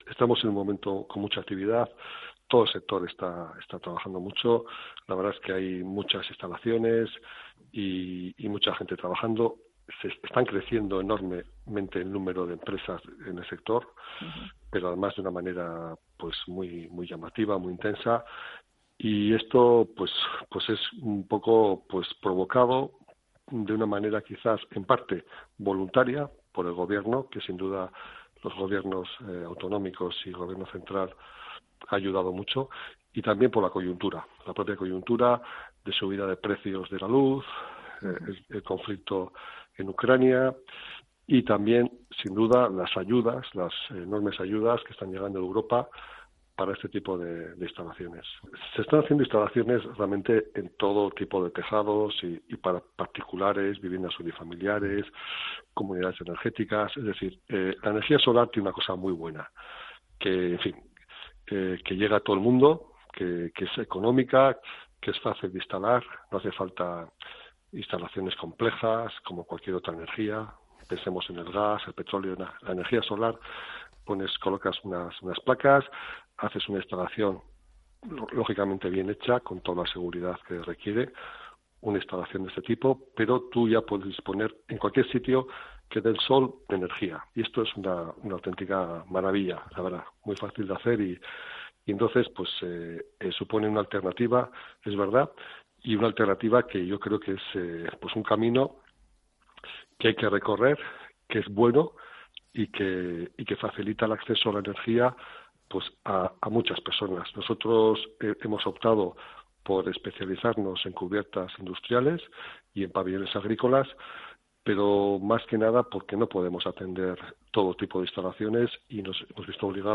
Entrevistado en el marco de la Semana de la Eficiencia Energética de Onda Vasca